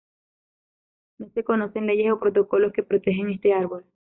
Hyphenated as le‧yes Pronounced as (IPA) /ˈleʝes/